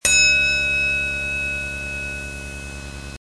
Elevator bell
Category: Sound FX   Right: Personal